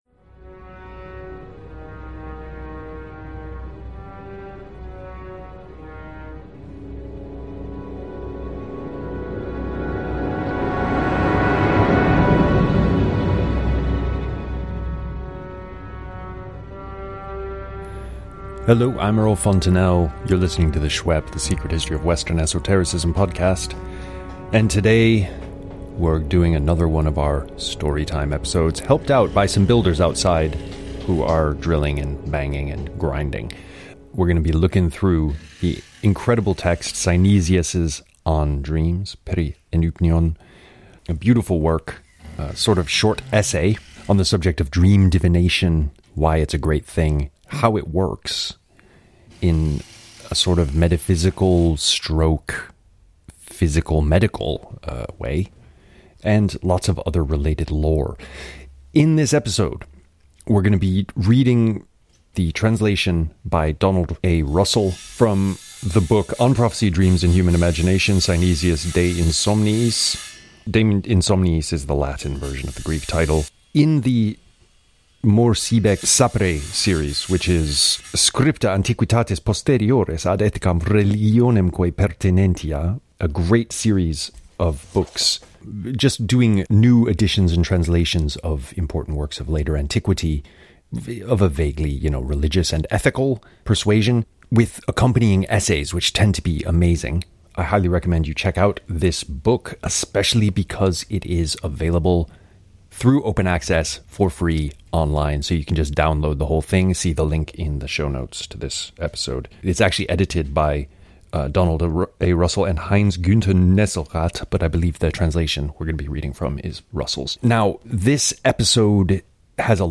home → synesius Synesius Texts of Synesius On Dreams (navigation in right-hand sidebar) English translation of On Dreams (PDF) SHWEP — Storytime: Reading Synesius On Dreams ( MP3 ) Related topics: Dreams Divination